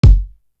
Ninety One Kick.wav